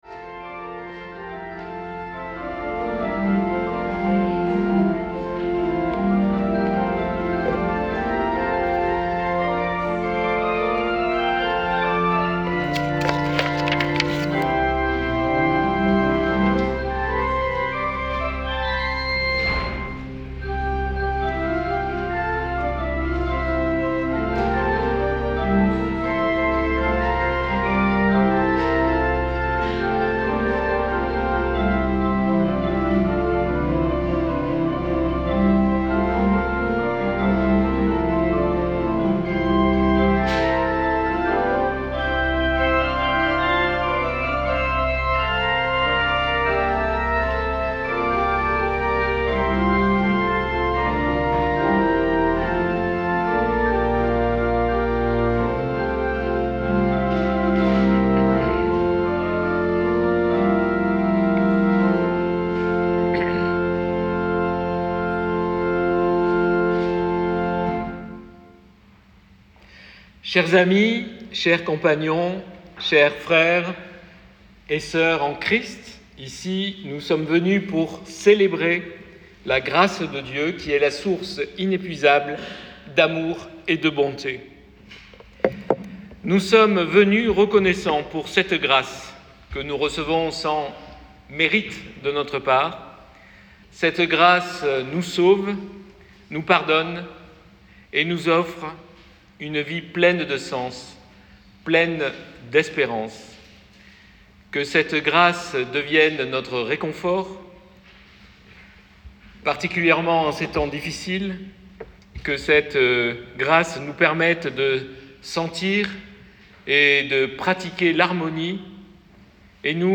" VA VERS LE PROFOND" Culte du 9 février 2025
Temple de Port Royal